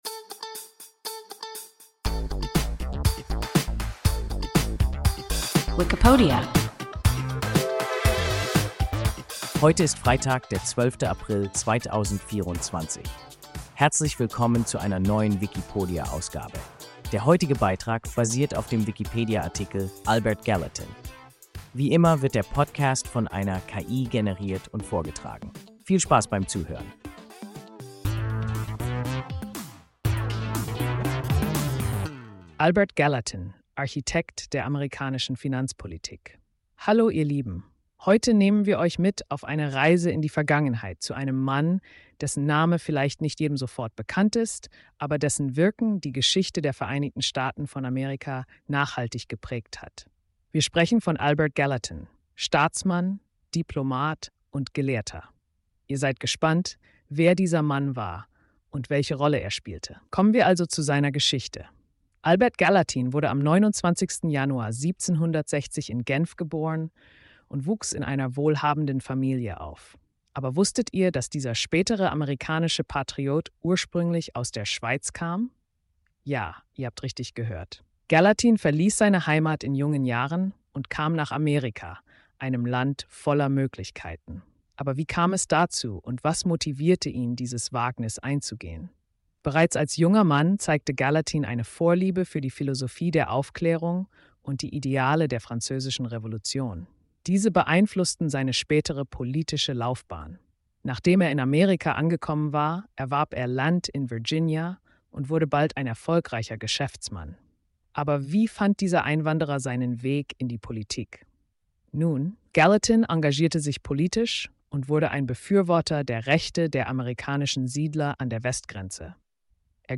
Albert Gallatin – WIKIPODIA – ein KI Podcast